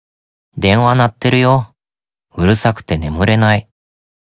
Notification Audio Files
Belphegor_Call_Notification_(NB)_Voice.ogg